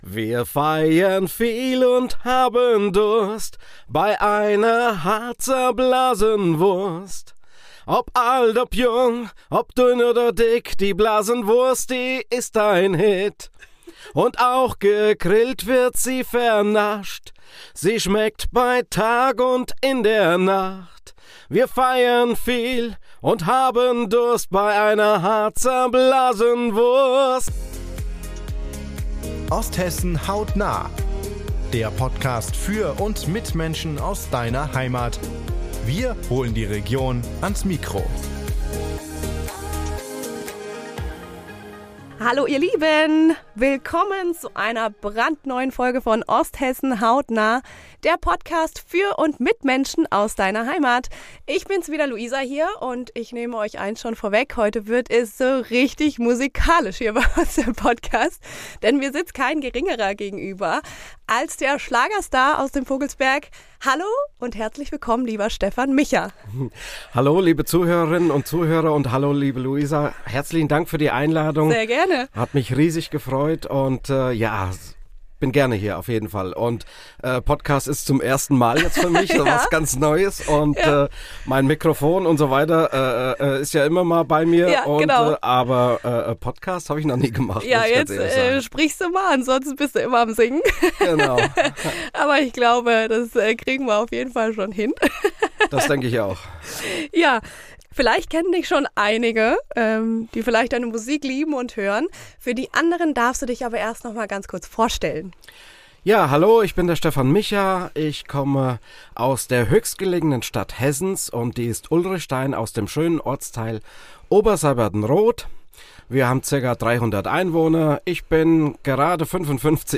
Es wird heute ganz schön musikalisch in unserem Podcast.